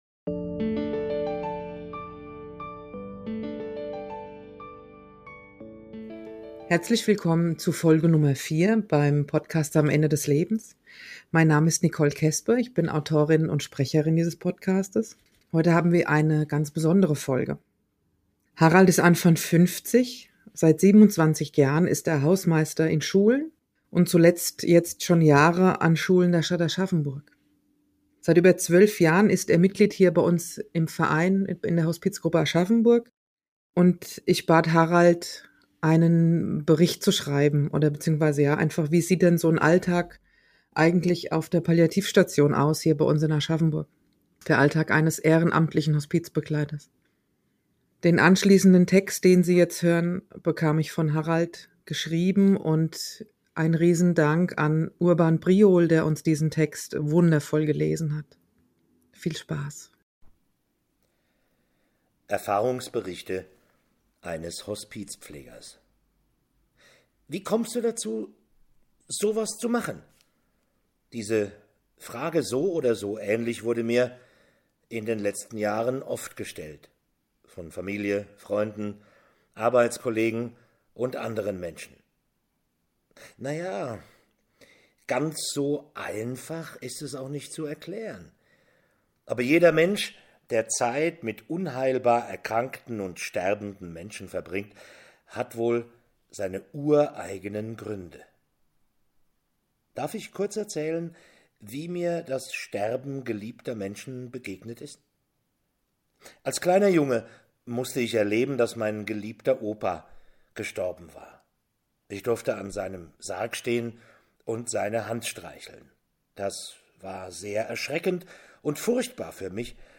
Ehrfahrungsbericht eines Hospizbegleiters, gelesen von Urban Priol
gelesen von Urban Priol.